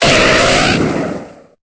Cri de Ponyta dans Pokémon Épée et Bouclier.